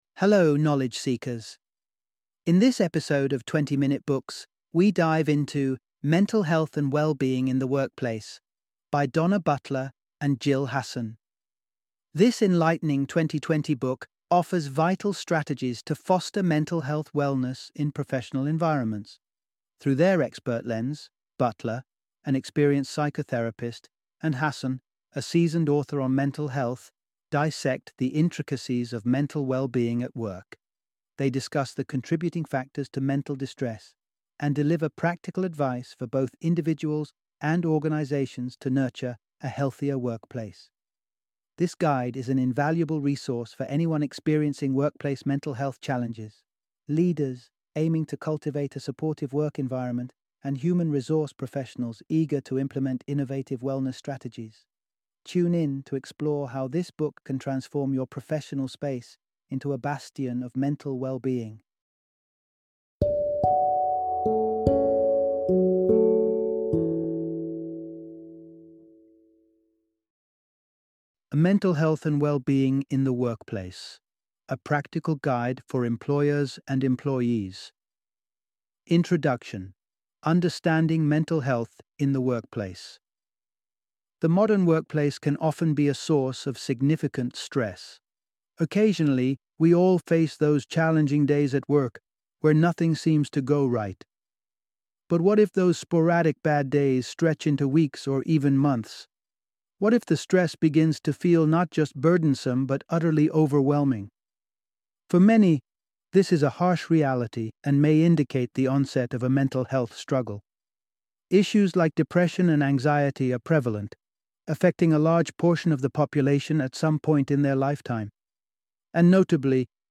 Mental Health and Wellbeing in the Workplace - Audiobook Summary